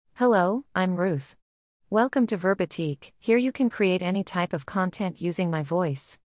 RuthFemale US English AI voice
Ruth is a female AI voice for US English.
Voice sample
Listen to Ruth's female US English voice.
Ruth delivers clear pronunciation with authentic US English intonation, making your content sound professionally produced.